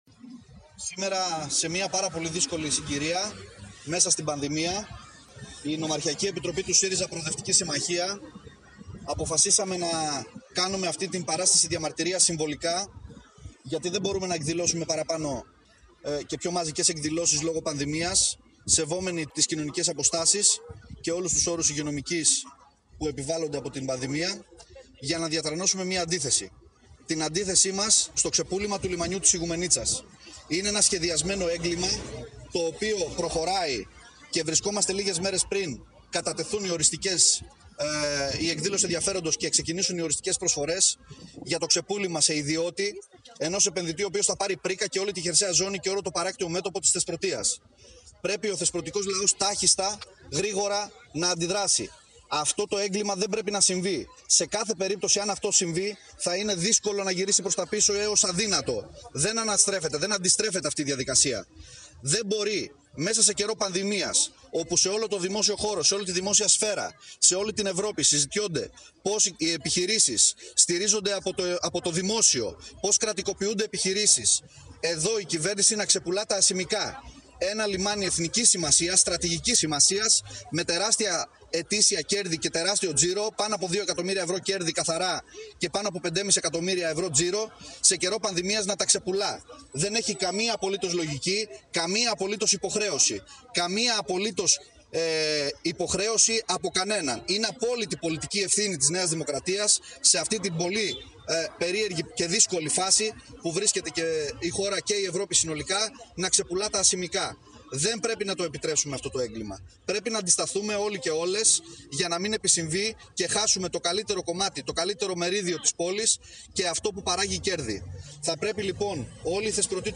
Ο Βουλευτής του ΣΥΡΙΖΑ στη Θεσπρωτία, Μάριος Κάτσης, σε δήλωσή του σημείωσε μεταξύ άλλων: